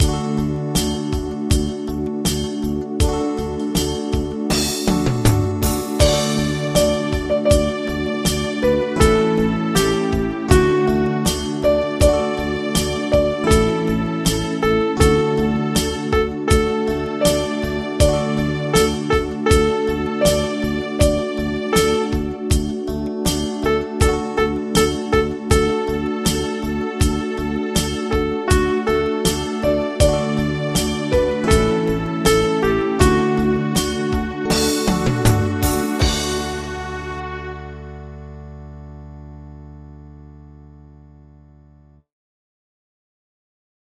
Audio Midi Bè Tenor: download